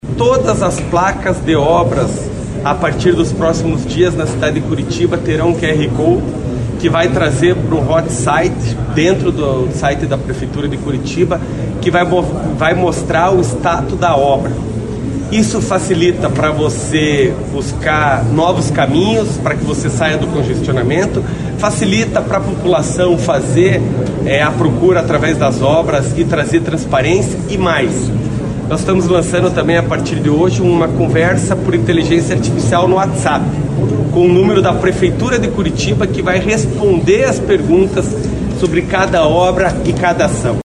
De acordo com o Prefeito de Curitiba, Eduardo Pimentel, o programa chega com uma novidade.
SONORA-PIMENTEL-OBRAS-1-GJ.mp3